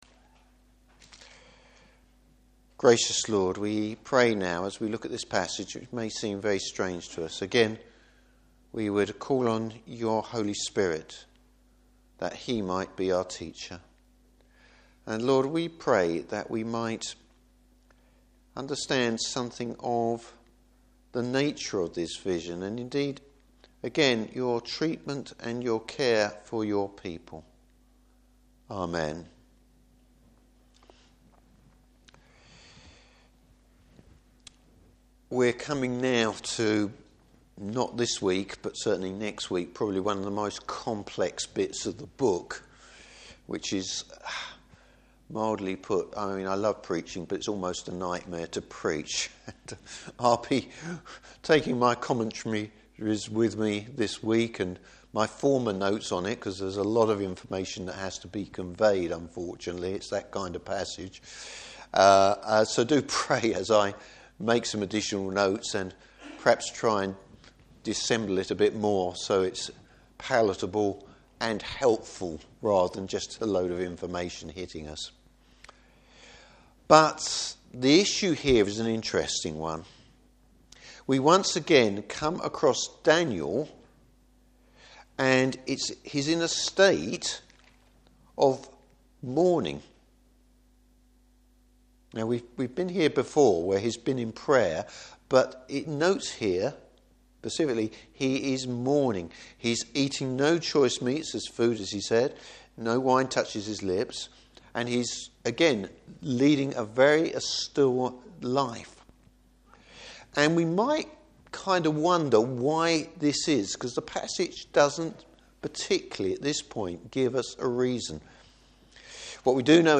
Passage: Daniel 10:1-11:1 Service Type: Evening Service The introduction to Daniel’s final vision.